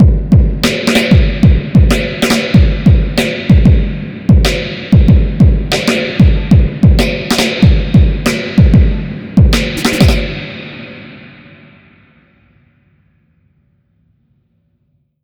Track 13 - Drum Break 01.wav